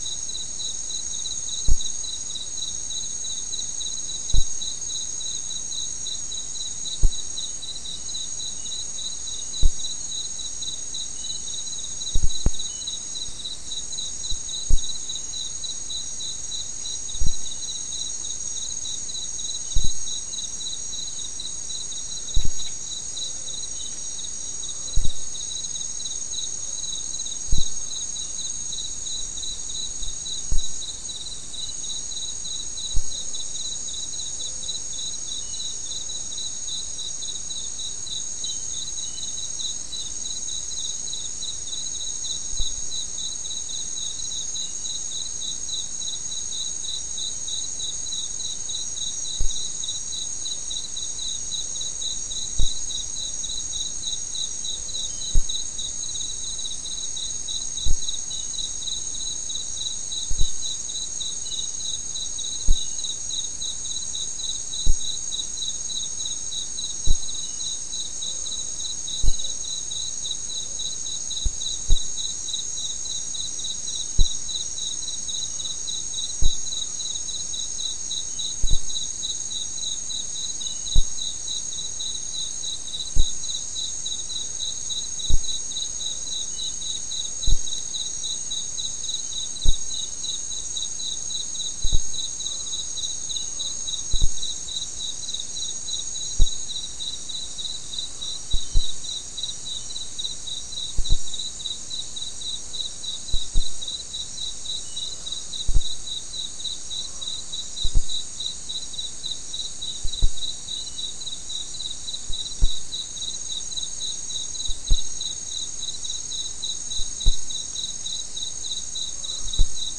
Soundscape Recording Location: South America: Guyana: Mill Site: 3
Recorder: SM3